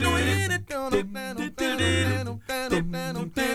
ACCAPELLA 8A.wav